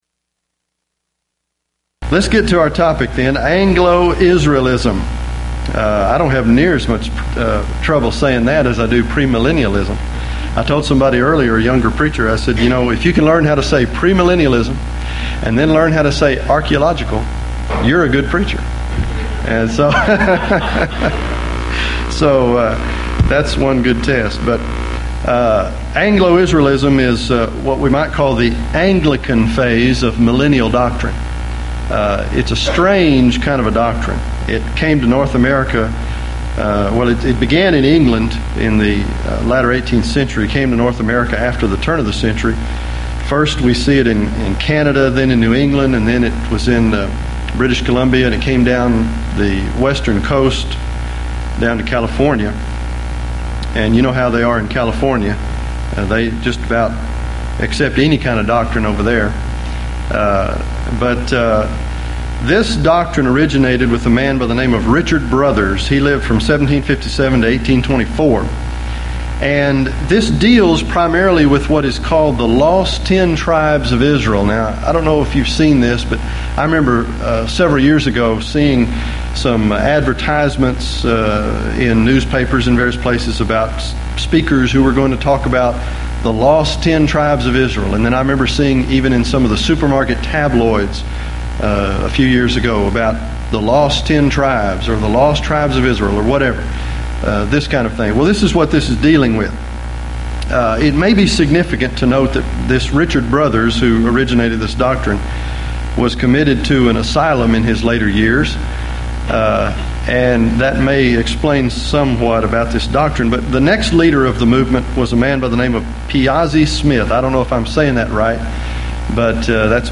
Houston College of the Bible Lectures